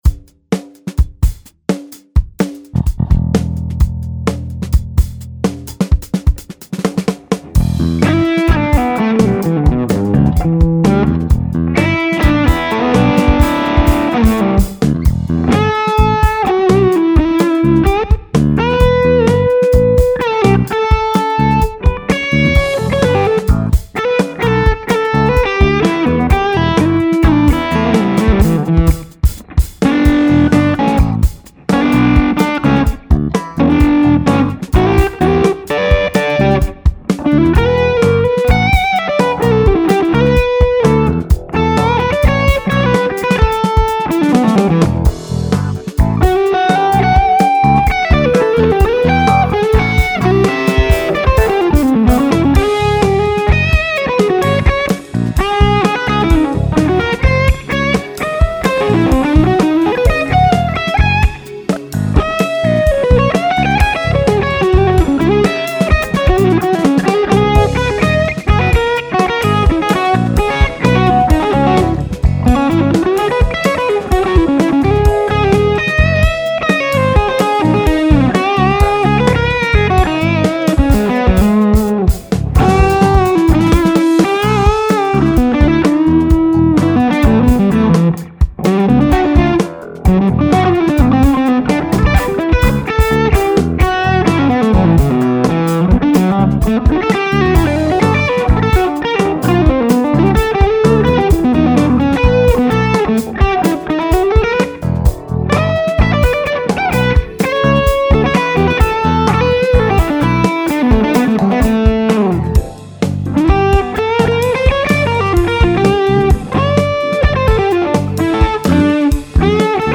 I did a scrappy take before work using the Bludo High Voltage Music Man Skyline amp.
Certainly the tone and sustain would be much improved with fresh ones.
Recorded in five minutes with lots of clams.
It sounds alot fatter than Robbens tone, mabe because of the tone settings and difference in trannies?
I really like the mid content but the whole spectrum is covered well and its got the bloom and response to pick attack that eludes some clones.